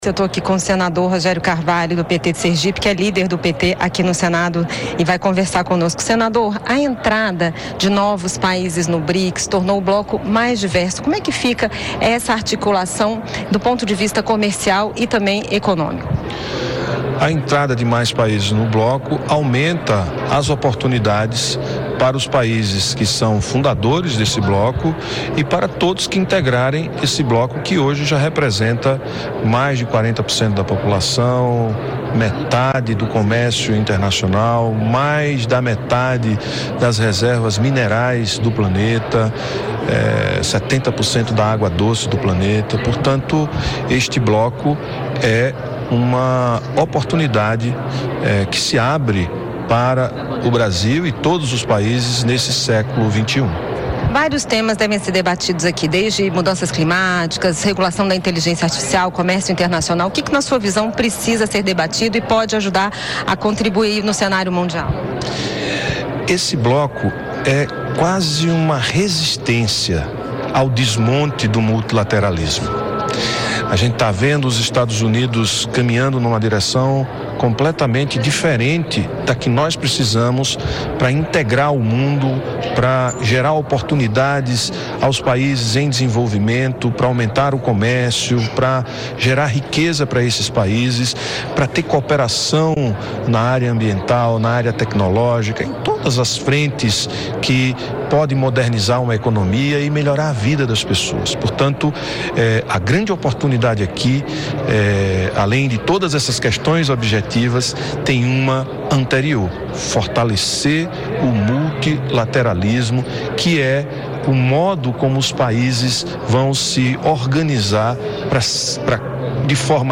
Em entrevista concedida nesta terça-feira (3), no primeiro dia do 11º Fórum Parlamentar do Brics, o senador Rogério Carvalho (PT-SE) declarou que o bloco é uma forma de resistência ao desmonte do multilateralismo.